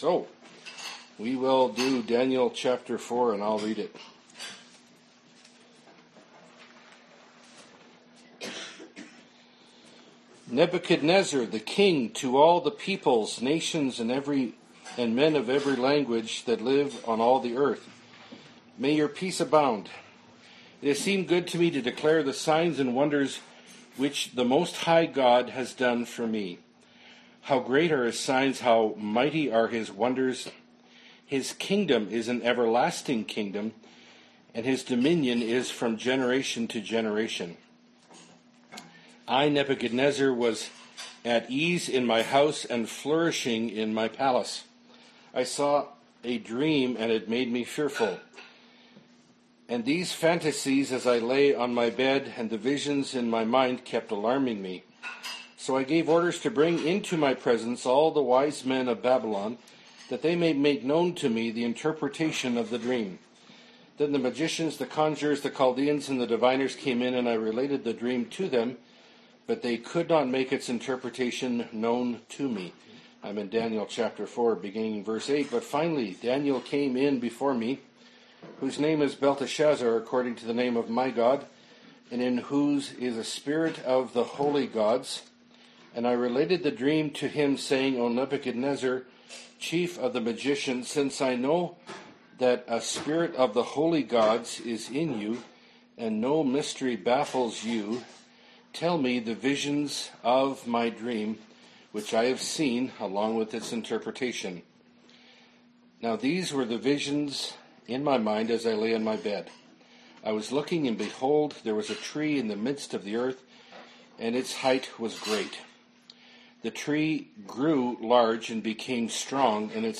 Bible Study - Daniel 4 - (2017)